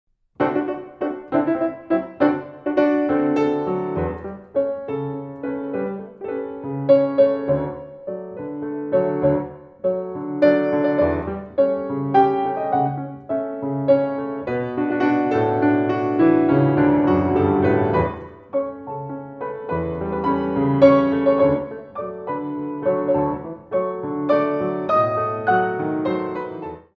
Saute